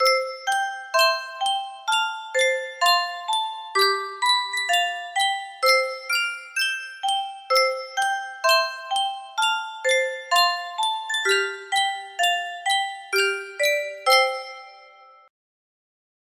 Sankyo Music Box - Little Brown Jug RTK music box melody
Full range 60